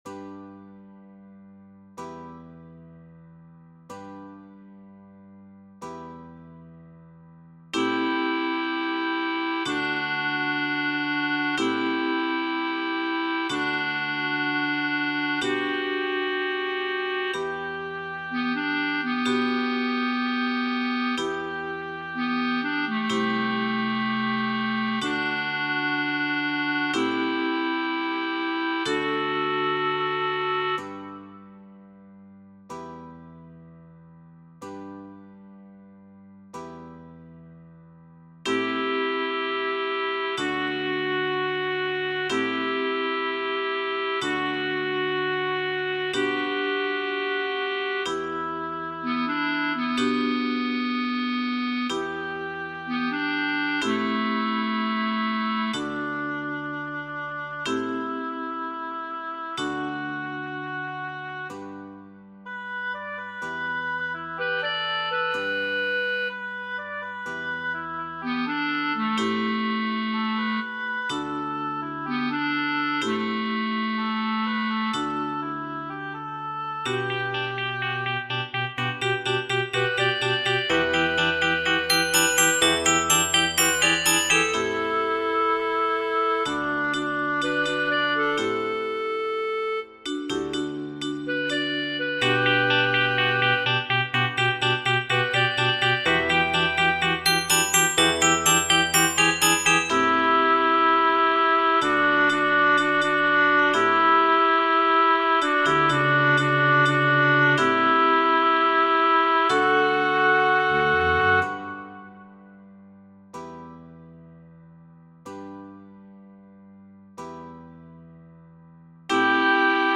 鉄琴のような
バッキングボーカル
フルート
トランペット、その他
アコーディオン、クラリネット、その他 ギター ベース